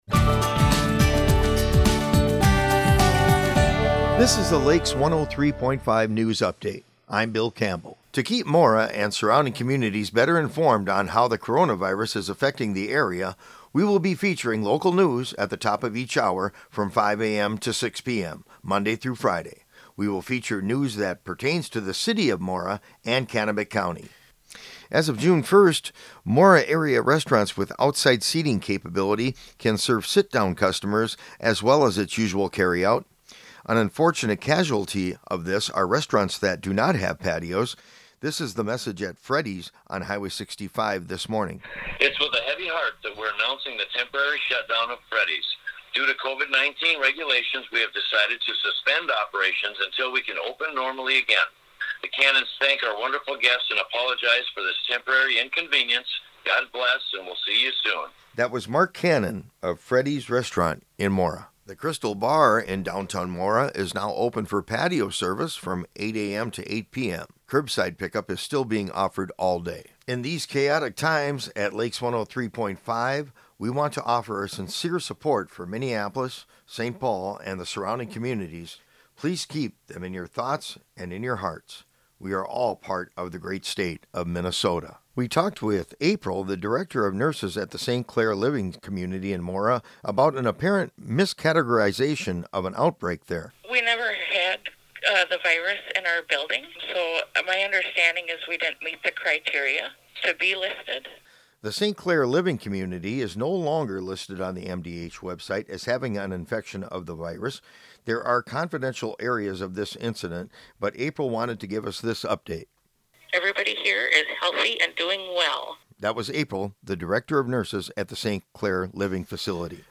This is an archived recording of a feature originally broadcast on Lakes 103.